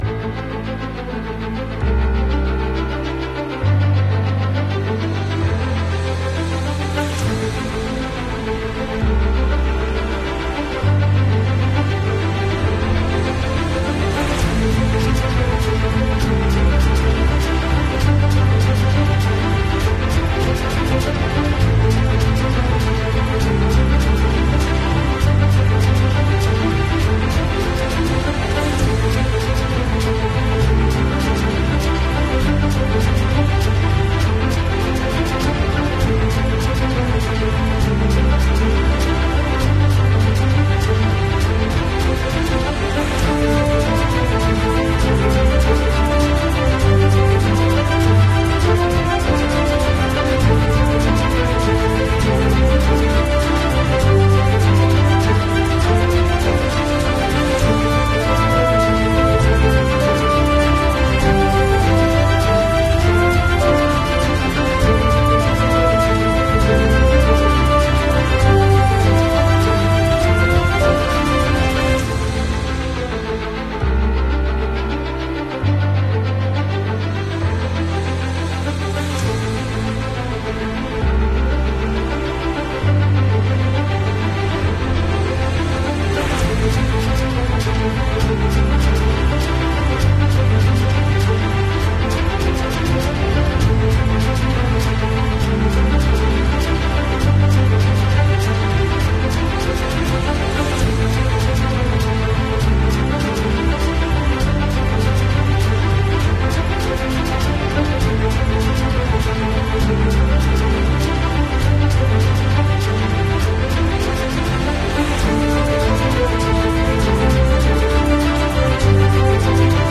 Podcast Madilog Forum Keadilan berikut ini mengungkap sisi pandang intelijen terhadap polemik yang menyebut nama Jokowi.